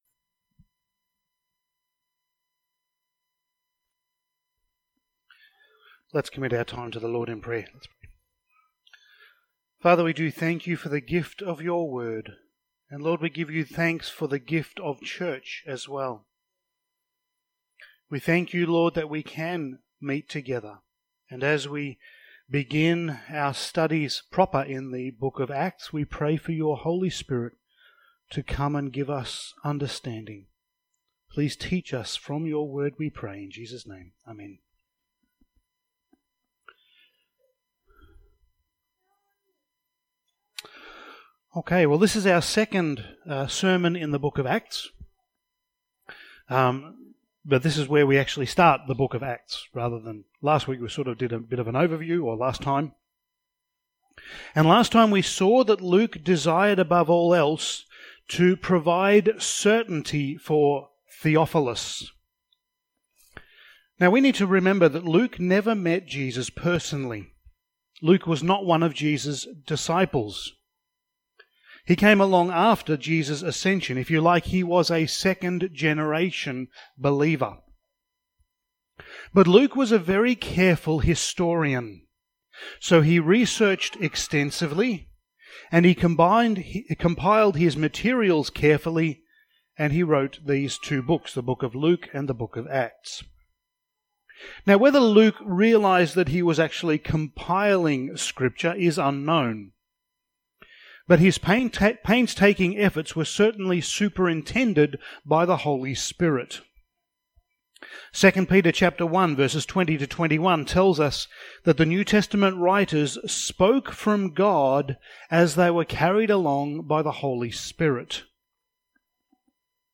Passage: Acts 1:1-11 Service Type: Sunday Morning